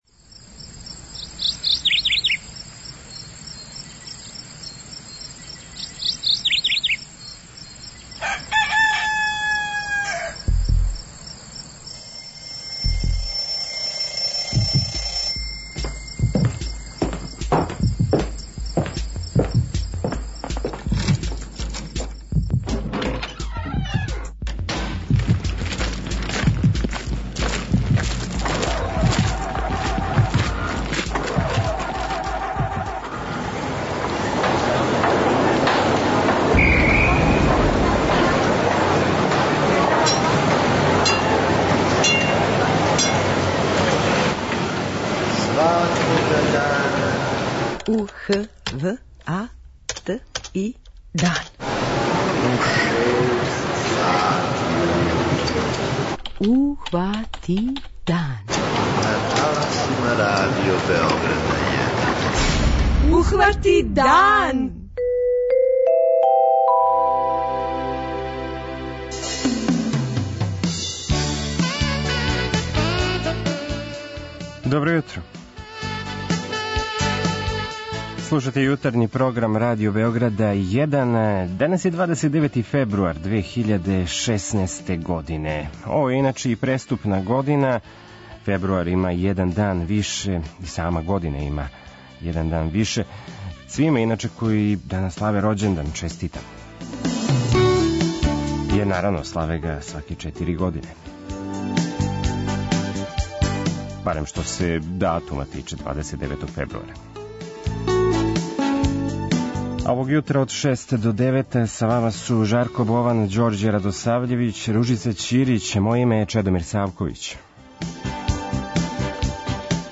преузми : 43.12 MB Ухвати дан Autor: Група аутора Јутарњи програм Радио Београда 1!